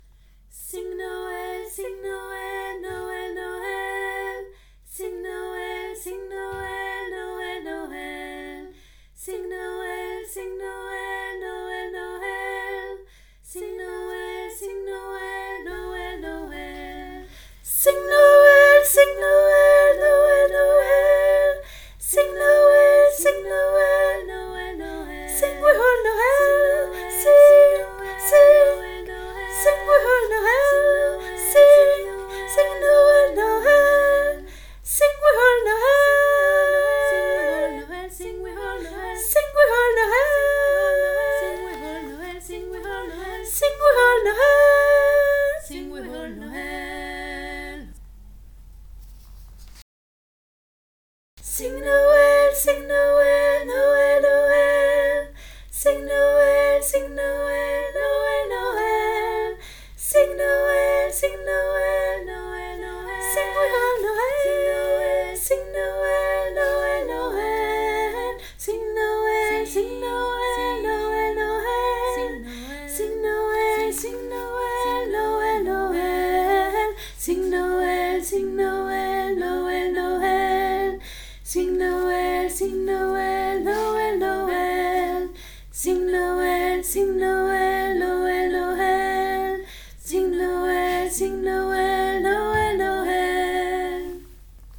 CHOEUR EPEHEMERE 2024
Sing Noel sopranes (aigu)
sing-noel-sopranes-aigu.mp3